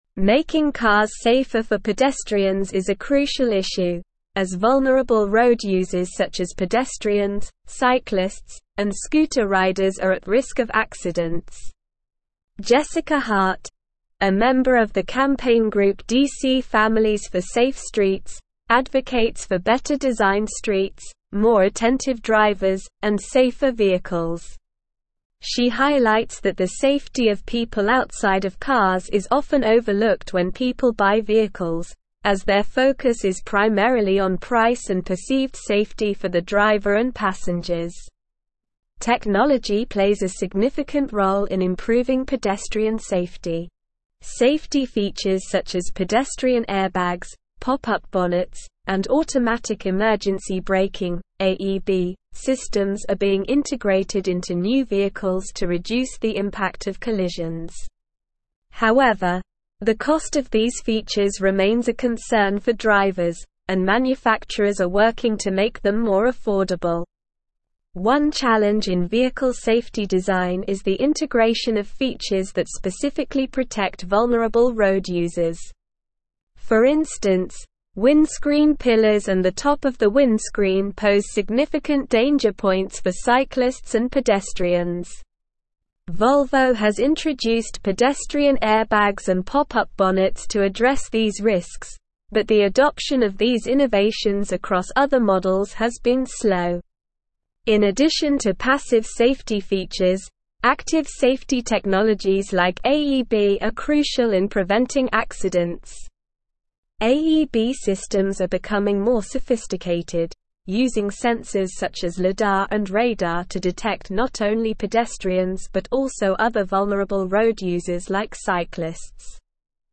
Slow
English-Newsroom-Advanced-SLOW-Reading-Advocating-for-Safer-Streets-Protecting-Vulnerable-Road-Users.mp3